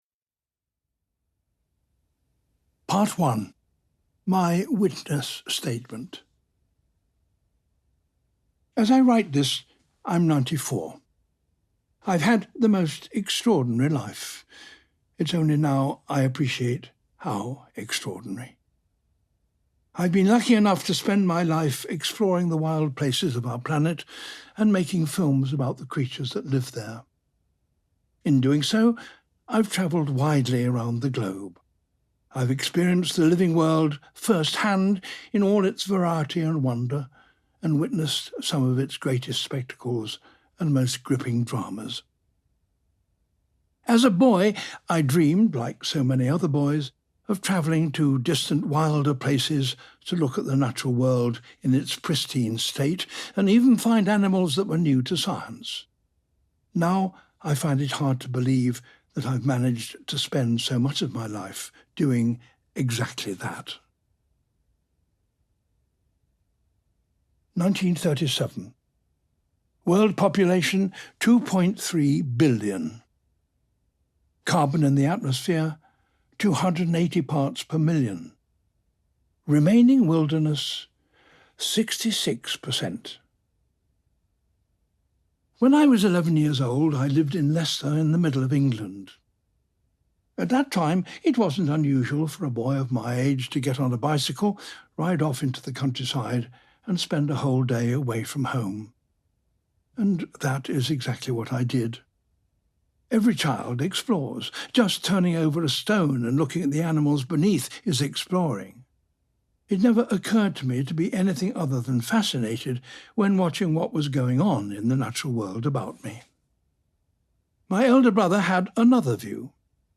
A Life on Our Planet by David Attenborough ｜ Penguin Audiobooks.mp3